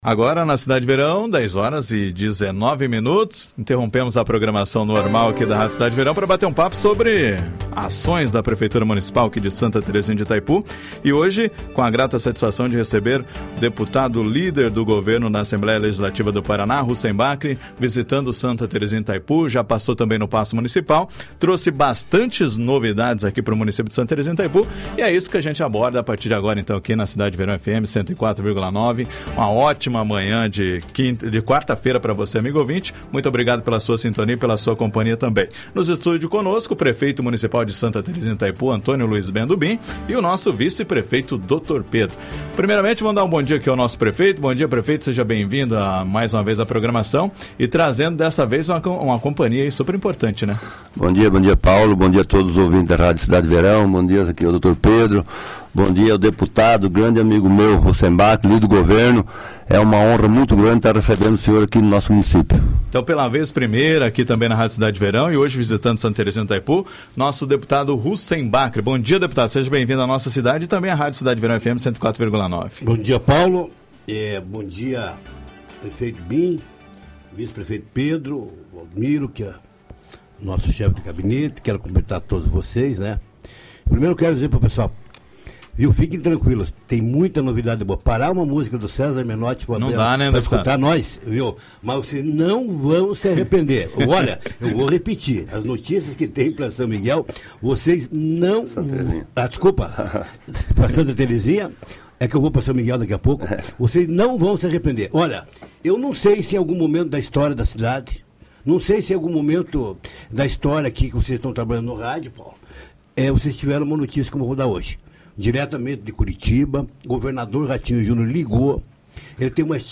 ENTREVISTA NA RÁDIO – Santa Terezinha de Itaipu celebra R$ 50 milhões em investimentos trazidos pelo deputado Hussein Bakri – Rádio Cidade Verão